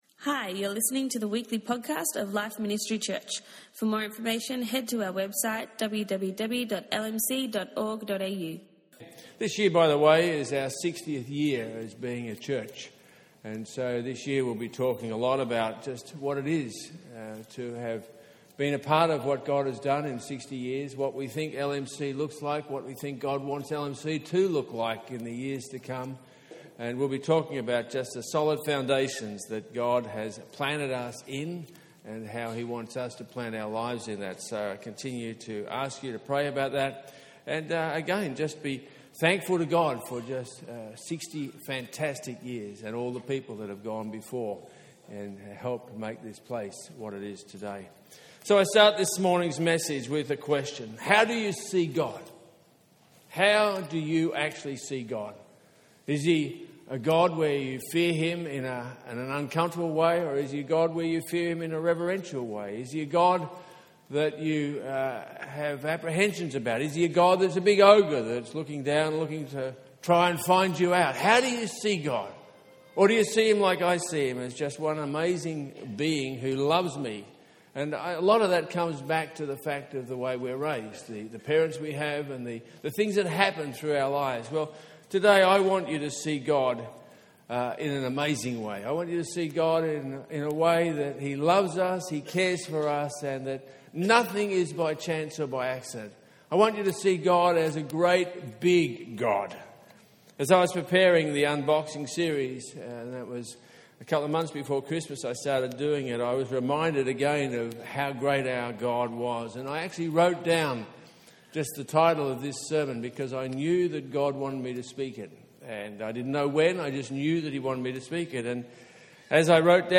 download the notes On our first Sunday of 2018